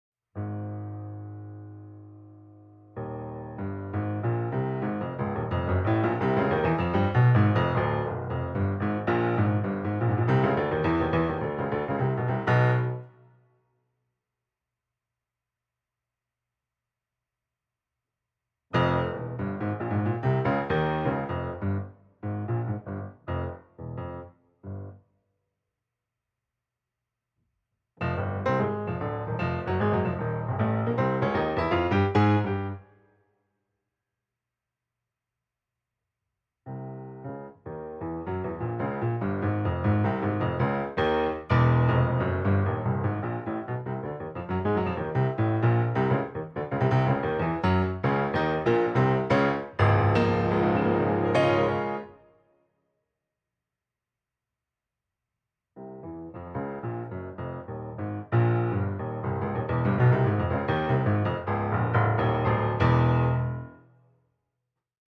electroacoustic improvisation